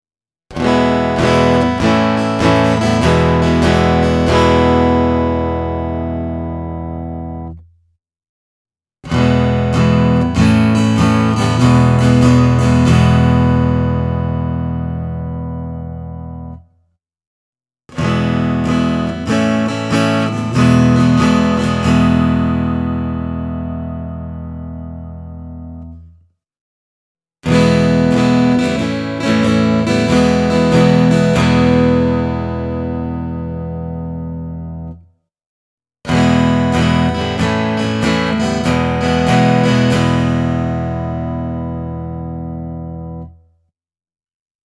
I have recorded three audio examples to give you an idea of the modelling technology’s sound:
The first clip features a Godin Acousticaster with an LR Baggs piezo system. First you’ll hear the straight piezo signal, followed by the these virtual guitars: A D-28, an OM-28, a 00-18 and an SJ-200. I’ve used Zoom’s virtual version of an AKG C414, and a touch of reverb: